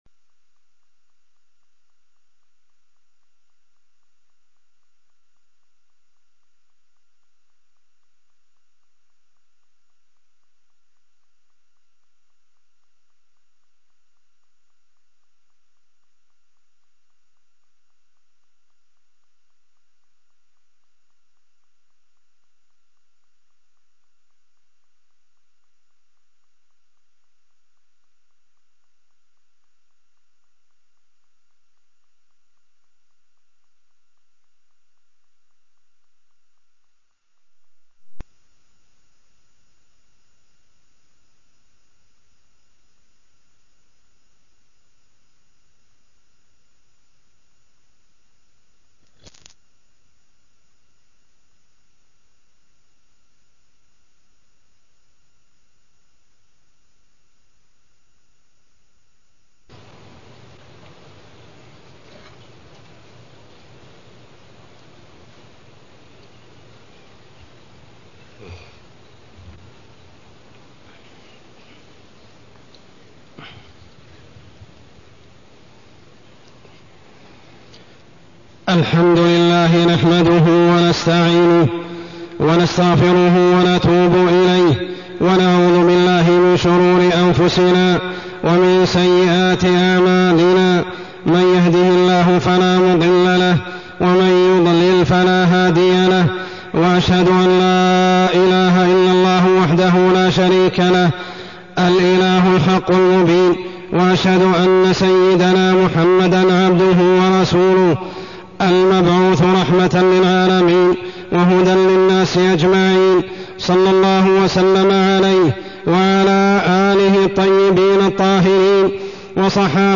تاريخ النشر ١٠ شعبان ١٤٢٢ هـ المكان: المسجد الحرام الشيخ: عمر السبيل عمر السبيل الفتن والبدع